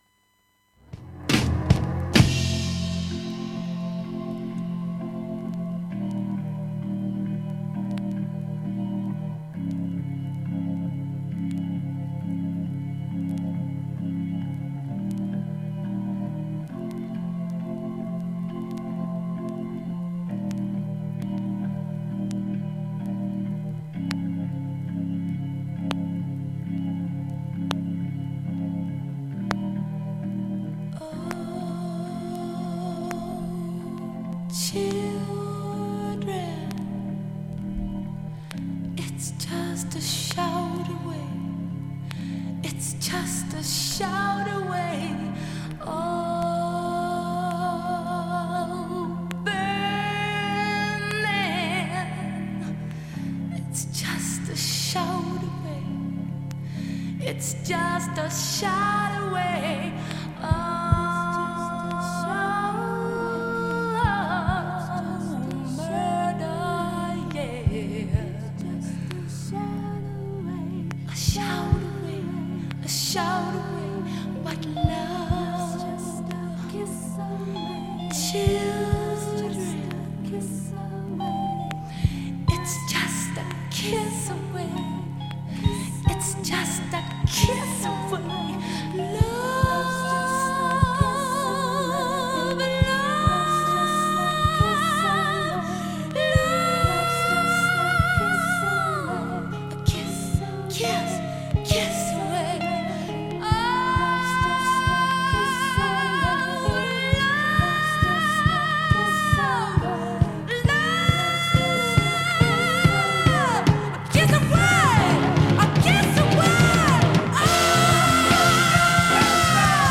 静かな部でもチリ音無しの
クリーントーンにて
轟音音質良好全曲試聴済み。
英国出身女性シンガー